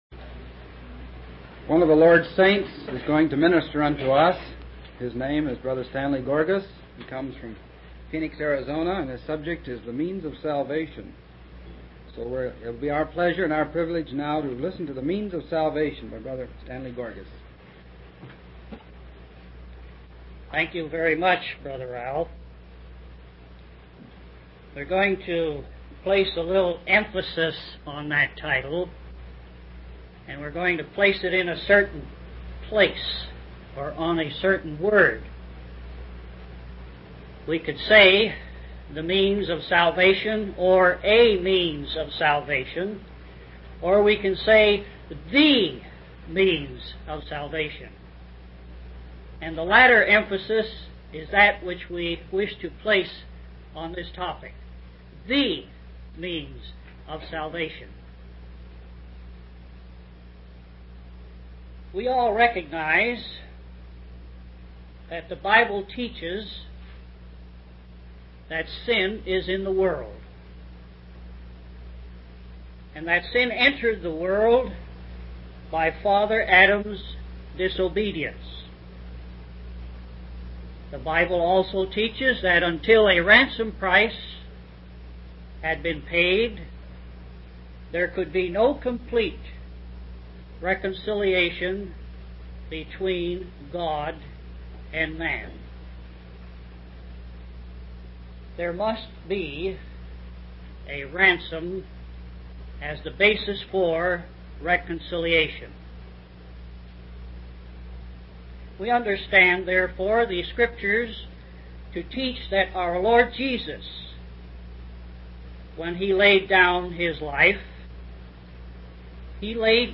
From Type: "Discourse"
Fort Worth Convention 1969